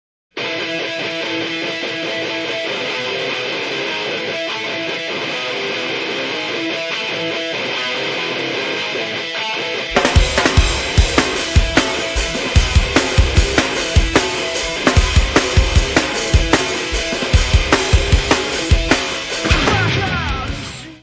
voice/guitar
drums
Turntables
Music Category/Genre:  Pop / Rock